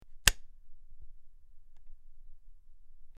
SwitchClicksOnOff PE447606
Switch; Clicks On And Off.